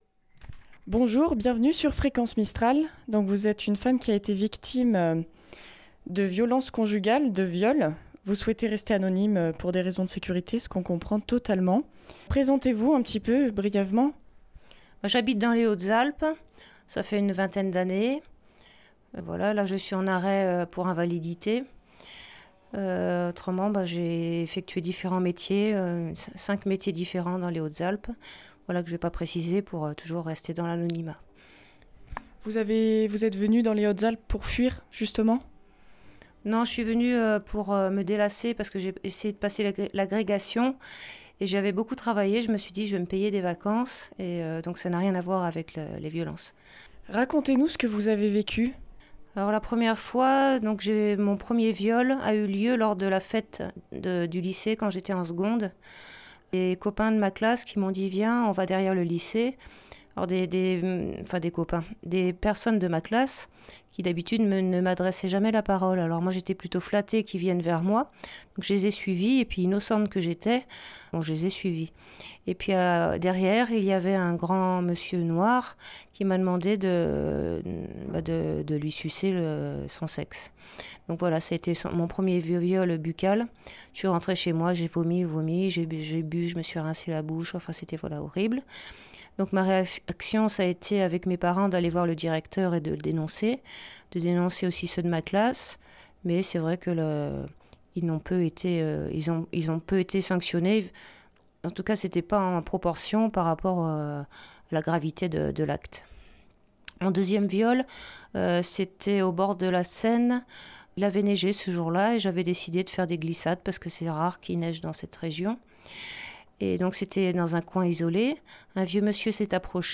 Témoignage Violence conjugale et viol
Une femme détruite, épuisée et isolée raconte son calvaire et comment elle s'en est sortit.